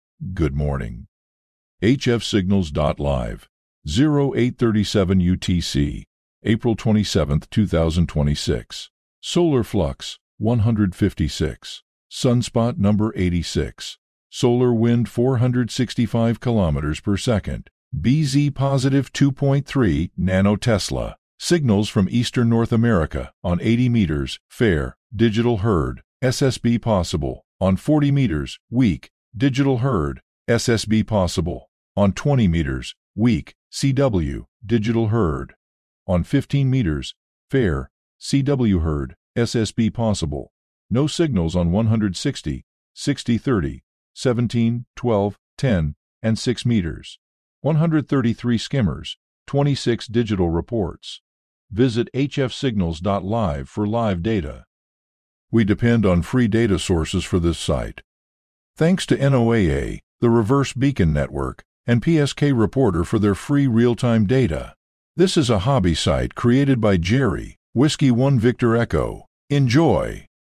AUDIO REPORT API — SPOKEN PROPAGATION BRIEFING
Returns an MP3 audio file — a synthesized voice report covering current band conditions, active digital and CW modes, solar flux index, K-index, and geomagnetic conditions.
Region report: ENA → ENA (all modes)